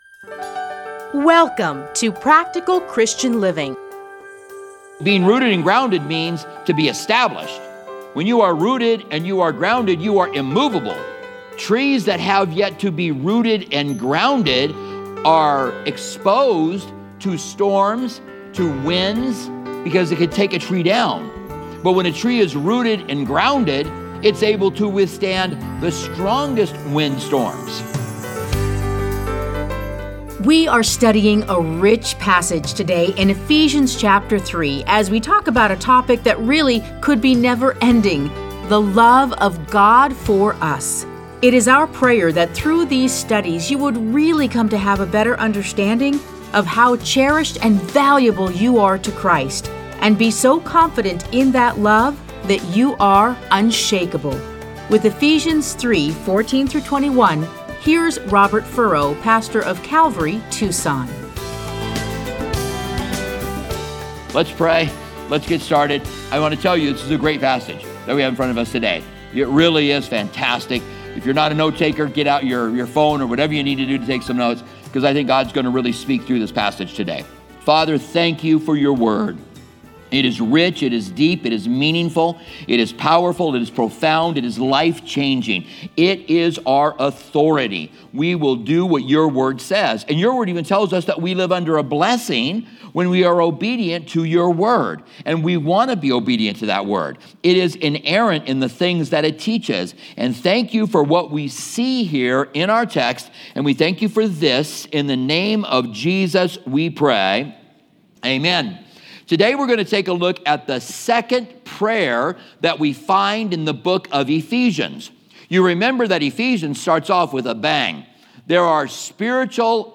Listen to a teaching from Ephesians 3:14-21.